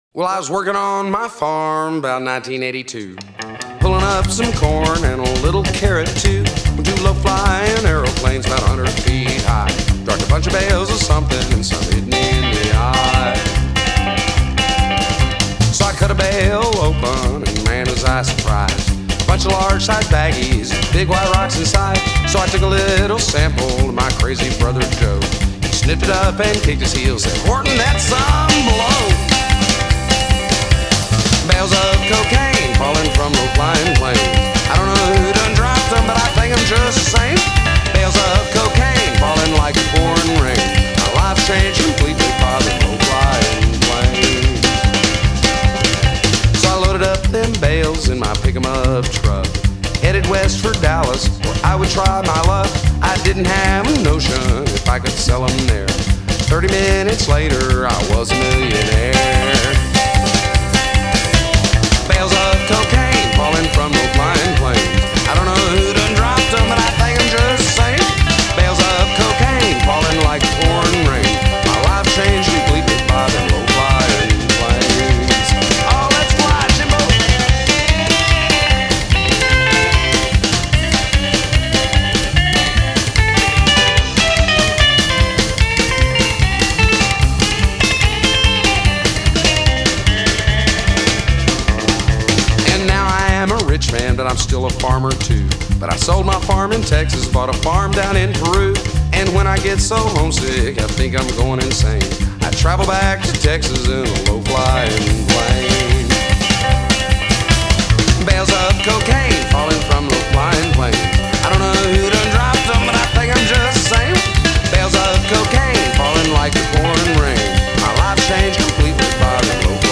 A must have for any Rockabilly fan, new or old.